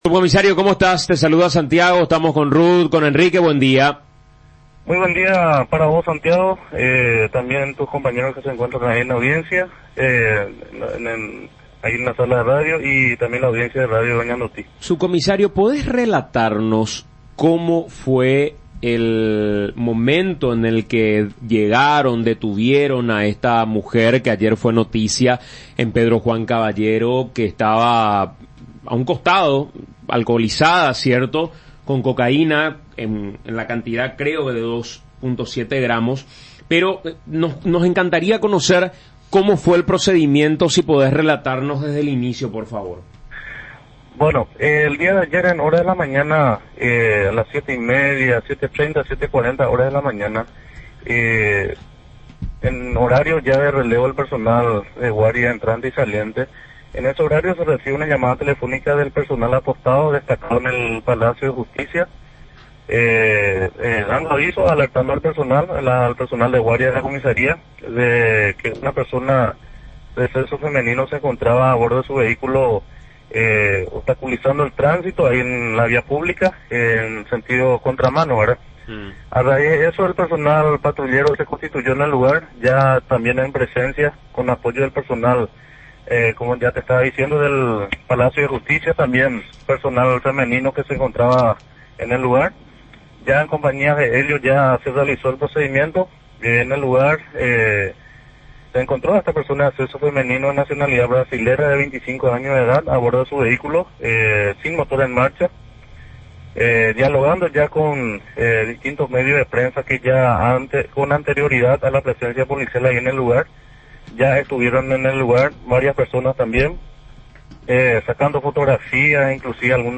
Declaraciones-del-subcomisario.mp3